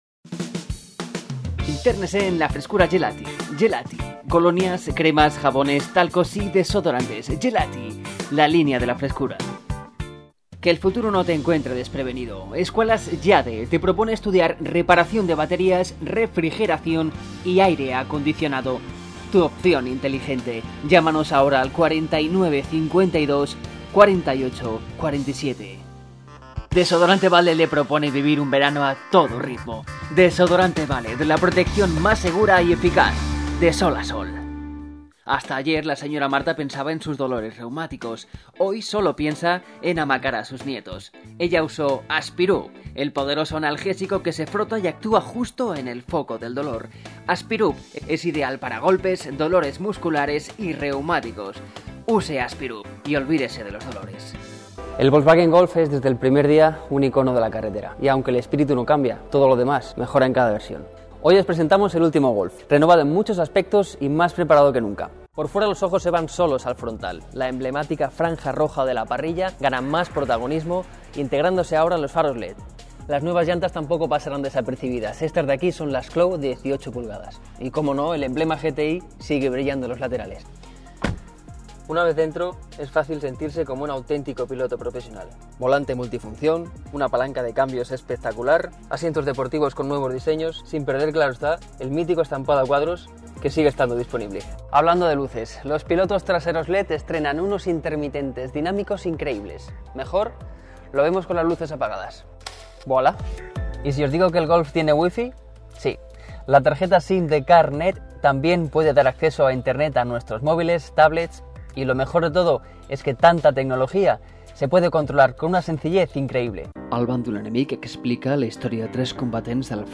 Voz juvenil, pero a la vez consistente, creíble y con diferentes tonalidades, siempre adaptándome a la necesidad del producto y del cliente.
Ofrezco una locución viva, con dinamismo, implicada al 100 % con el producto del cliente, en una entrega de menos de 24 horas y en alta calidad dado que dispongo de estudio propio profesional de grabación y una respuesta al cliente rápida y con prespuestos acordados en un breve tiempo.
kastilisch
Locuciones.mp3